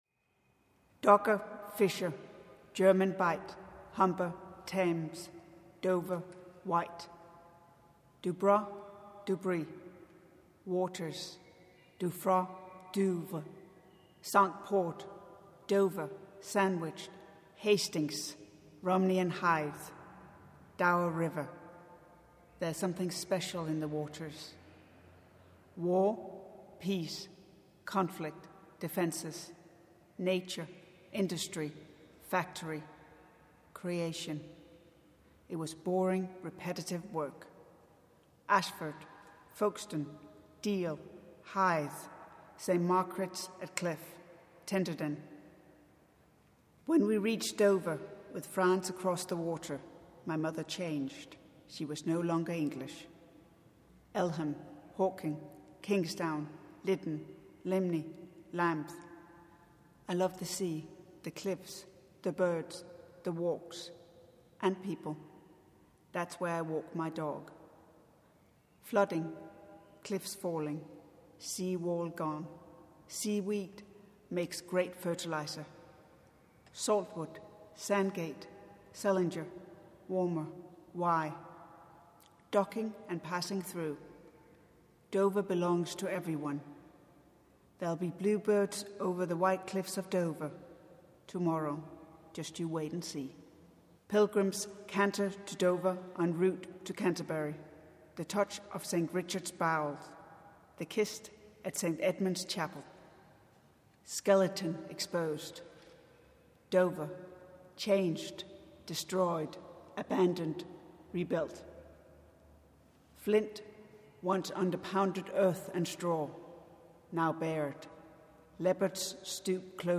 (read by the Author)
(Both recorded at All Saints Tooting)